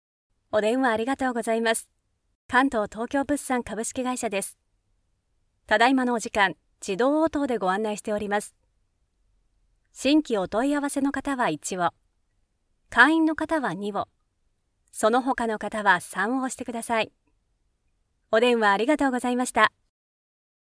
5. Phone 00:21
If you’re looking for a voiceover that is based in japan i have many years of experience, and i can help you with your next project.